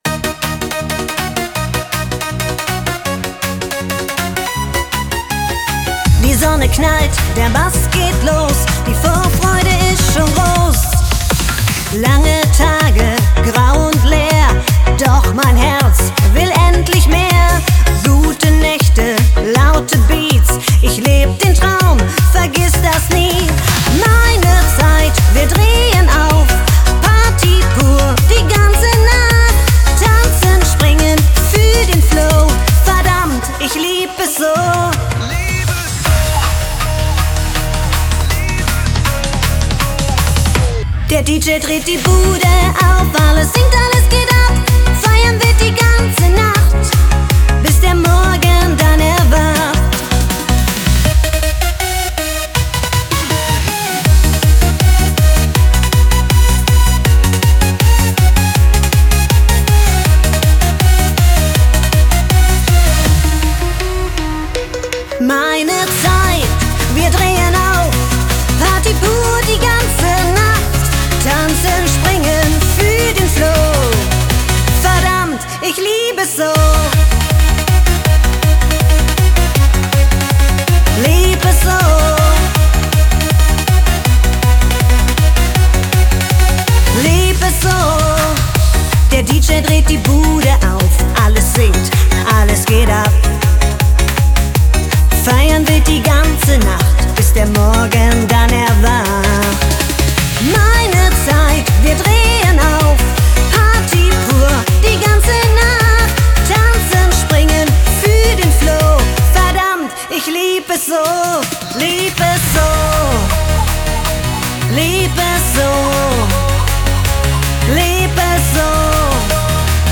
KI-Mallorca Stil / KI-Schlager:
Mallorca Song 160bpm Track
Ballermann / Schlager: